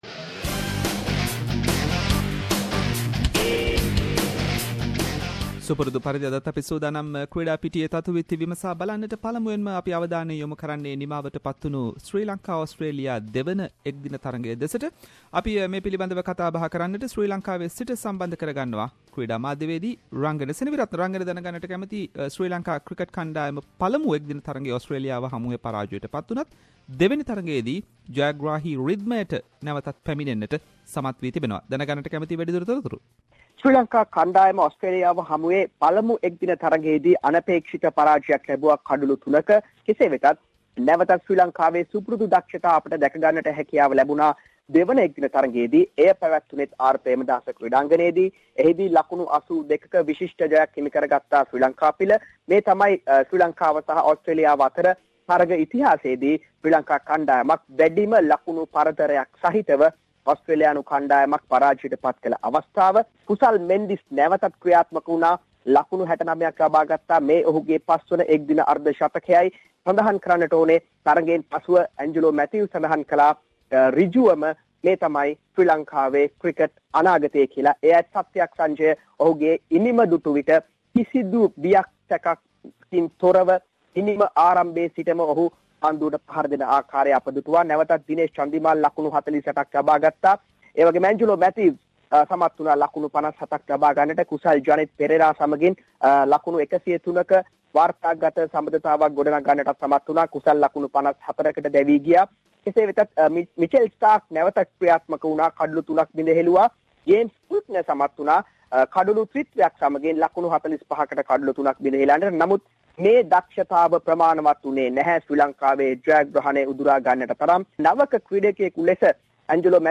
Weekly Sports wrap